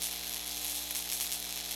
electric.mp3